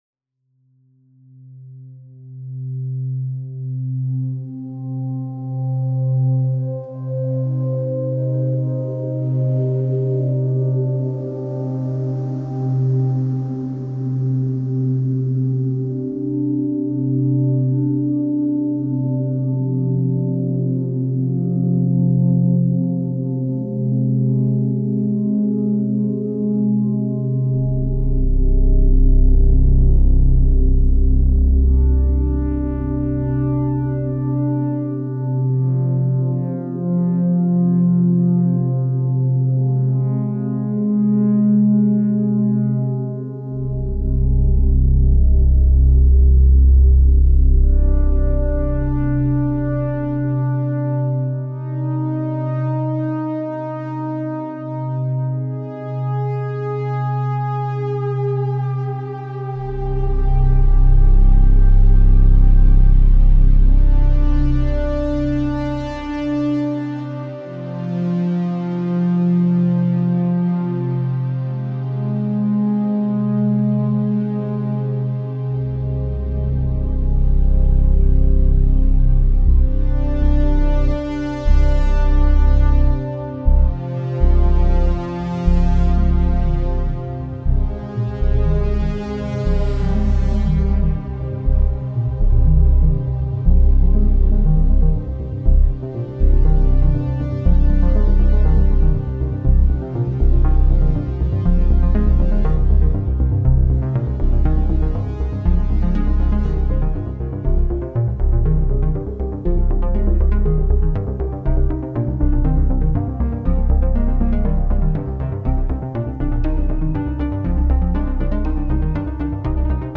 Трек сведённый без плагинов. С живой реверберацией.
Записал и свёл музыку без использования плагинов. В работе был использован один синтезатор Behringer Model D. Эквализация и компрессия сделана на железе.
Все звуки, включая бочку, сгенерированы на синтезаторе. Тарелка записана с моей барабанной установки, плюс ещё пара записанных эффектов. В треке использована реверберация записанная в лесу и большом зале.